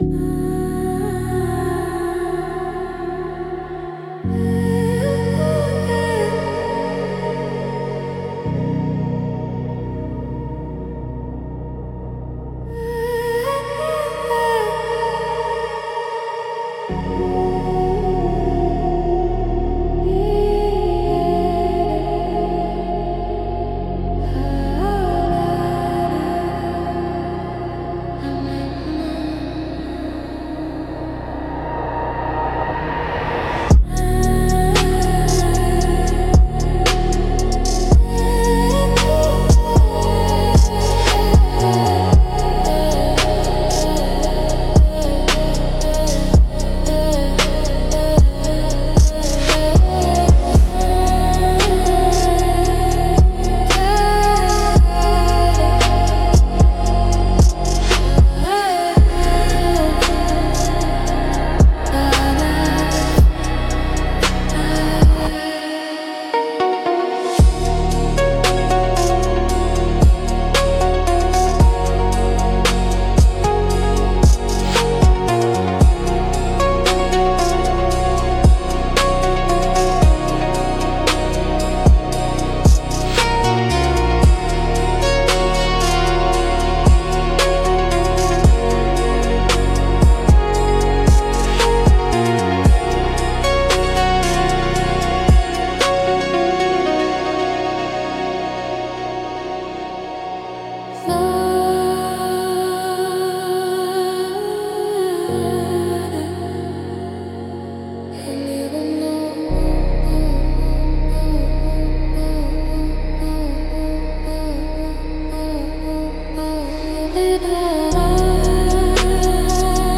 Instrumental - Detuned in a Dream 3.26